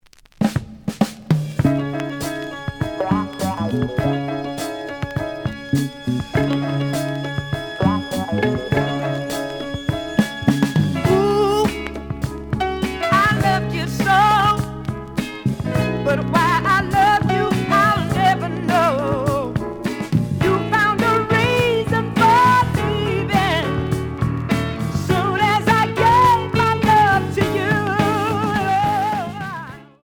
The audio sample is recorded from the actual item.
●Genre: Soul, 70's Soul
A side is slight cracking sound.